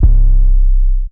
MZ 808 [Plugg #2 - C].wav